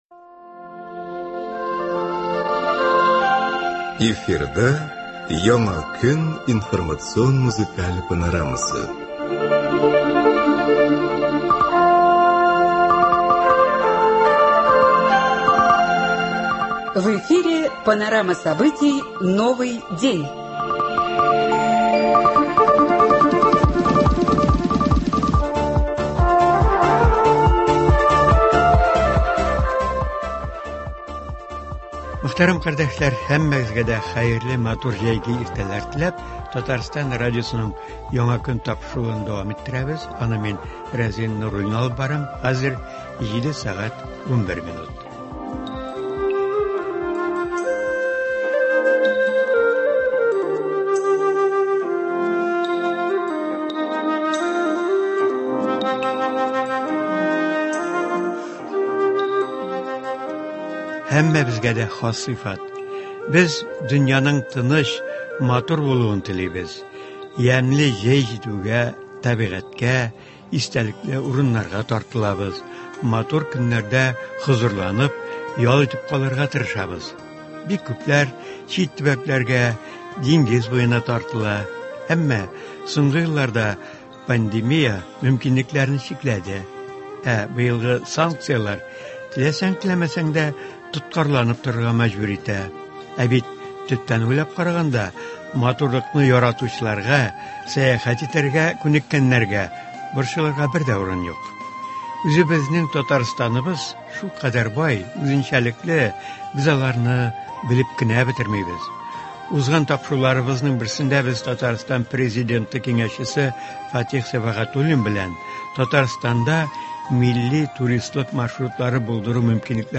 Саба районының Миңгәр авылында “Миңгәр-тур” шәһәр яны сәламәтләндерү базасын тәкъдир итүгә багышланган түгәрәк өстәл үткәрелде. Анда танылган язучылар, артистлар, район вәкилләре катнашты. Тапшыруда шушы очрашудан репортаж тәкъдим ителә.